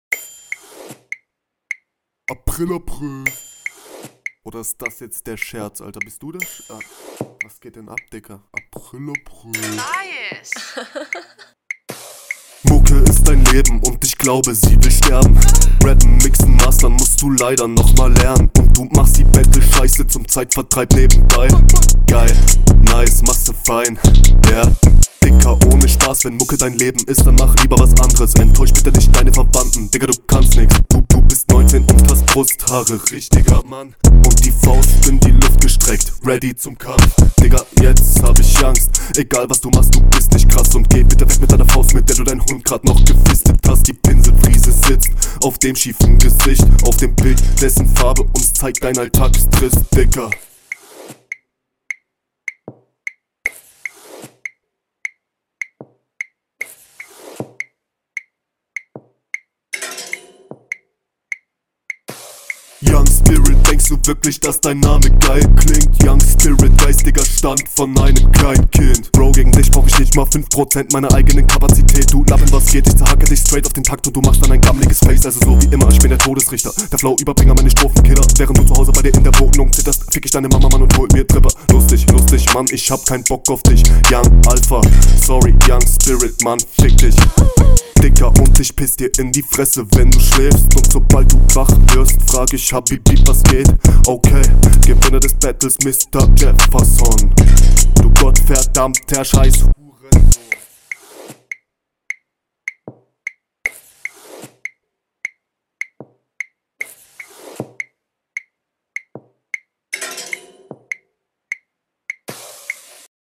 Flowvariationen sind geil.
Der Beat steht dir sogar nochmal besser, sehr anspruchsvolle Patterns dabei, alle sehr ausgereift.